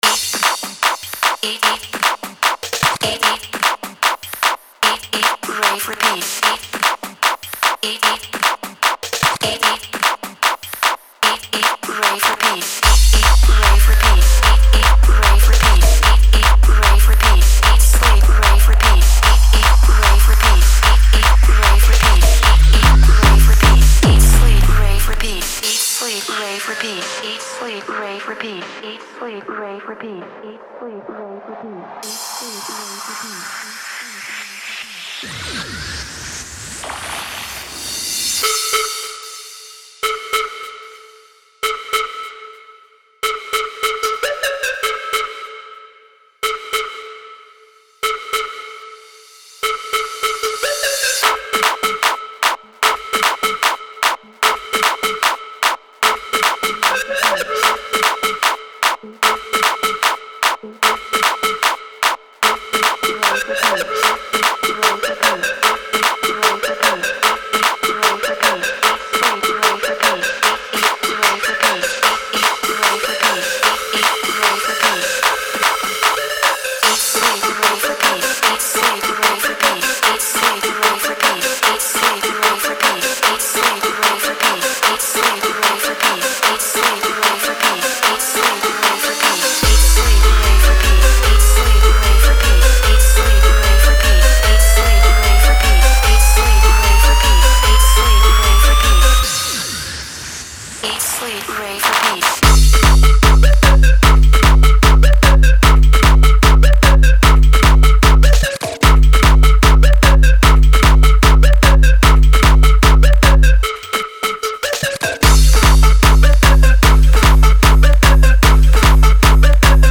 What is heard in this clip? Género: Jumpstyle.